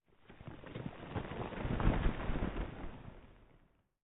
Minecraft Version Minecraft Version snapshot Latest Release | Latest Snapshot snapshot / assets / minecraft / sounds / ambient / nether / nether_wastes / ground1.ogg Compare With Compare With Latest Release | Latest Snapshot